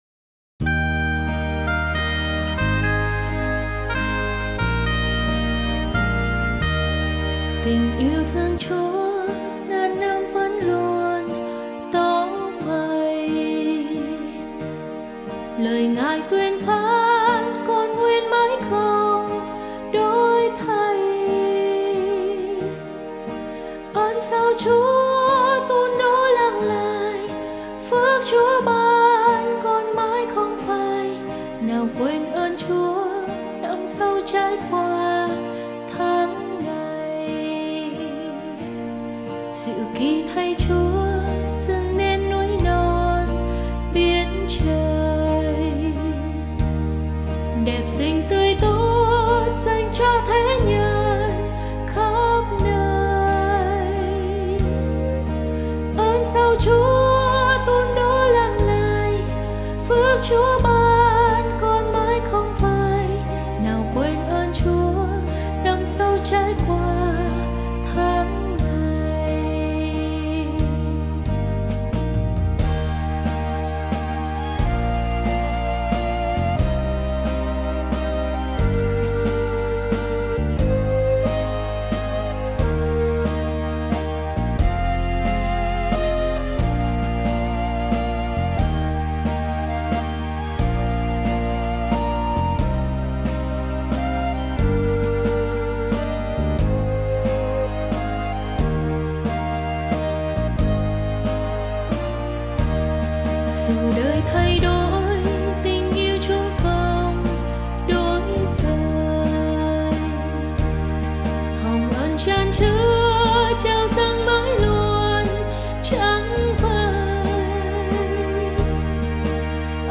Nhạc Thánh Sáng Tác Mới